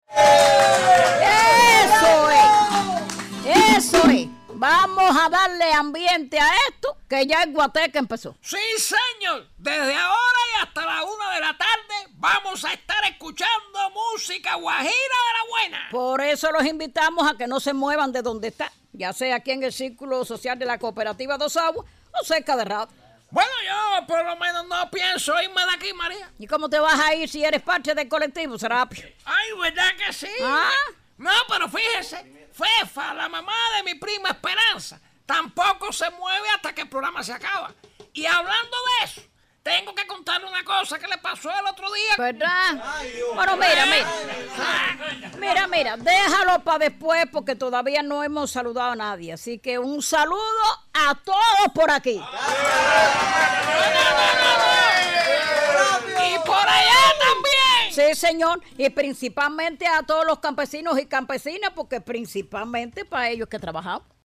PROGRAMA-CAMPESINO.mp3